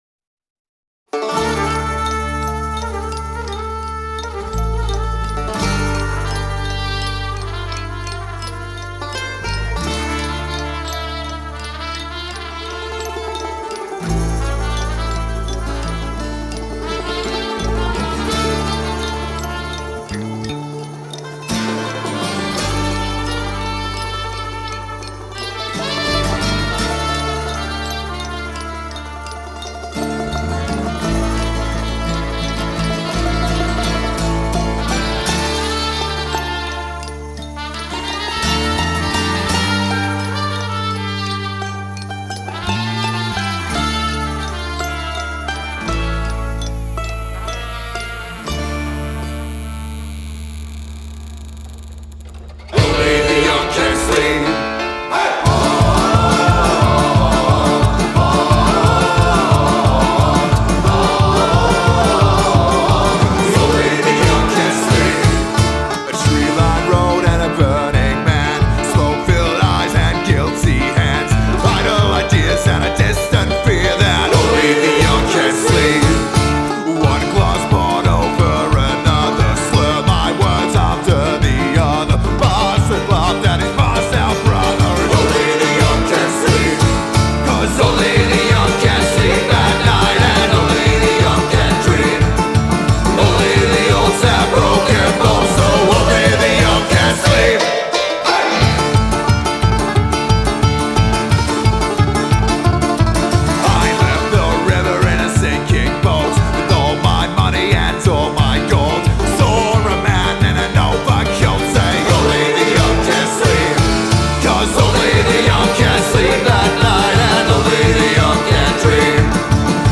Genre: Folk, Roots, Alternative